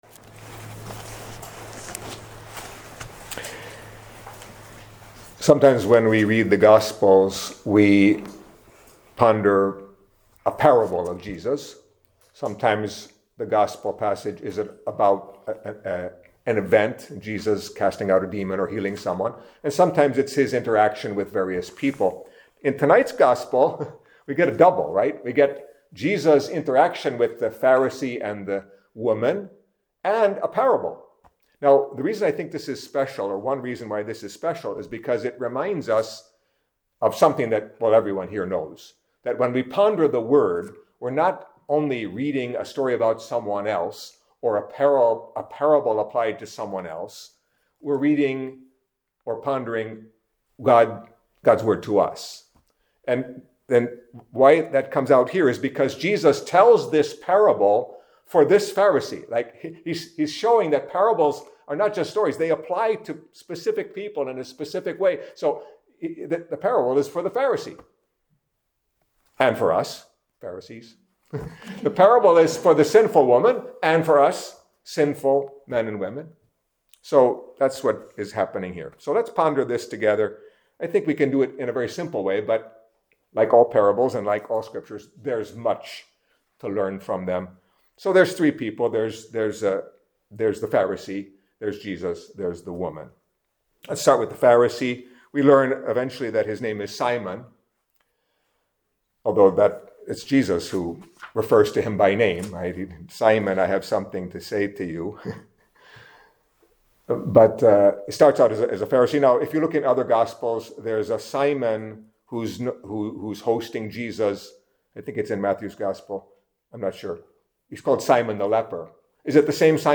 Catholic Mass homily for Thursday of the Twenty-Fourth Week in Ordinary Time